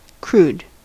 Ääntäminen
IPA : /kɹuːd/ US : IPA : [kɹuːd]